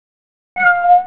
cat.au